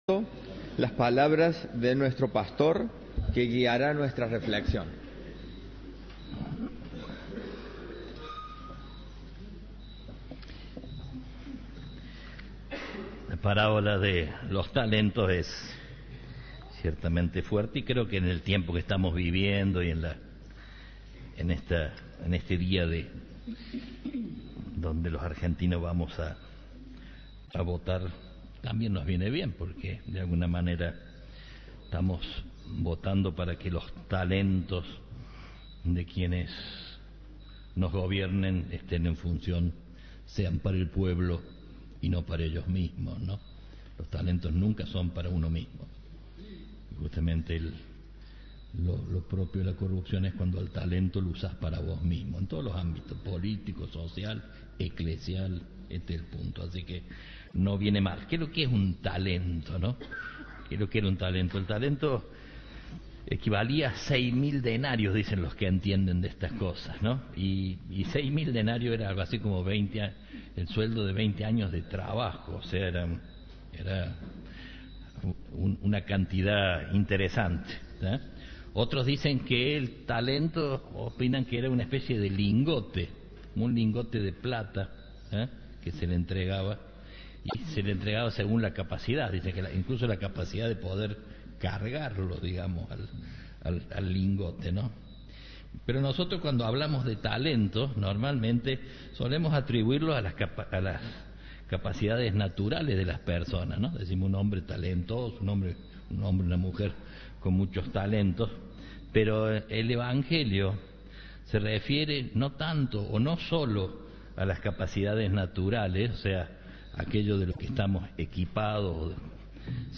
El arzobispo de Córdoba consideró que la fe, la oración y la caridad fueron dones legados que llevan al hombre a un estado superior. La homilía completa.